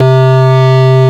FARFISA4  C2.wav